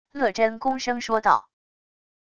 乐珍恭声说道wav音频生成系统WAV Audio Player